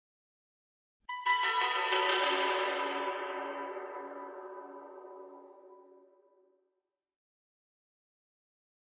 Chinese Koto Arpeggio 1 - Reverb